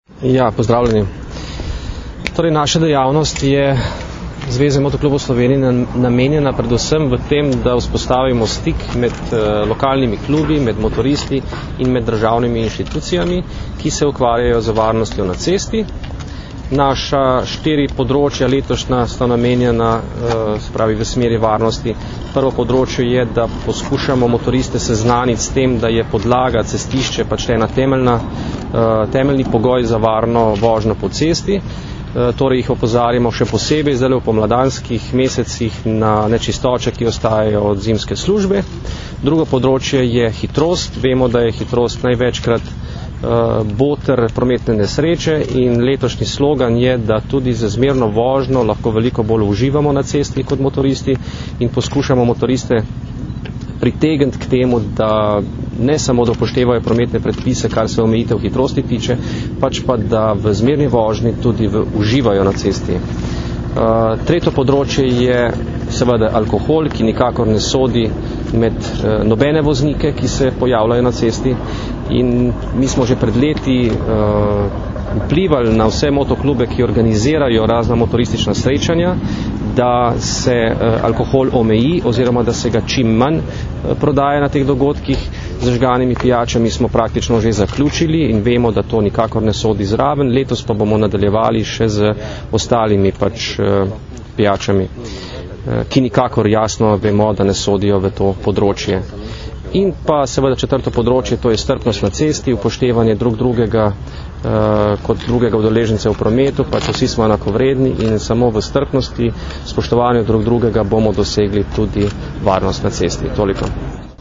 Naj bo motoristična sezona prijetna in varna - informacija z novinarske konference
Zvočni posnetek izjave